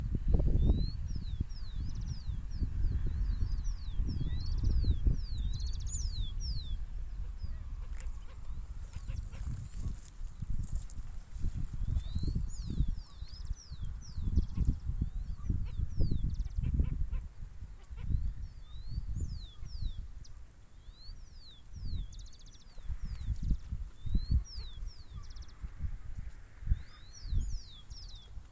Talha-mar (Rynchops niger)
Nome em Inglês: Black Skimmer
Localidade ou área protegida: Reserva Natural y Dique La Angostura
Condição: Selvagem
Certeza: Gravado Vocal